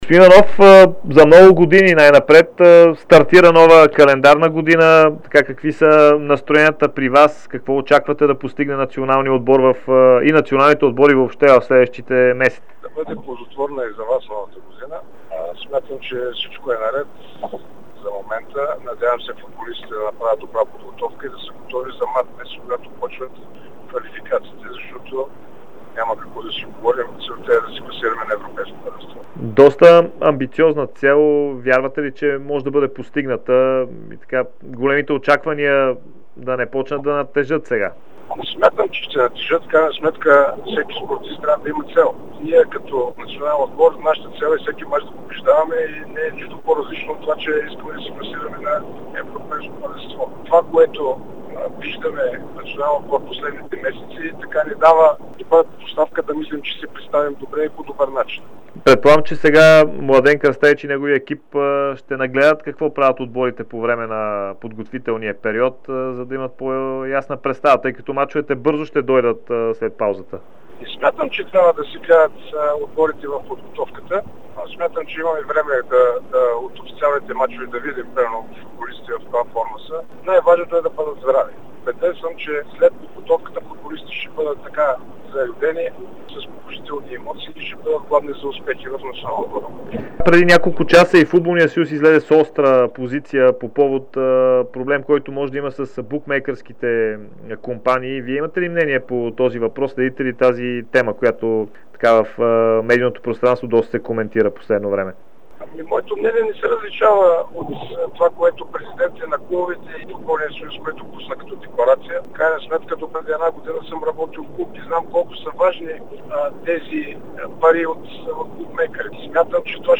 Техническият директор на Българския футболен съюз Георги Иванов – Гонзо заяви в специално интервю пред Дарик радио и dsport, че основната цел пред националния отбор е класиране на Европейското първенство през 2024 година. Той също загатна, че се очаква футболист на Аугсбург, който е с български корени да получи паспорт.